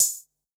Index of /musicradar/ultimate-hihat-samples/Hits/ElectroHat D
UHH_ElectroHatD_Hit-25.wav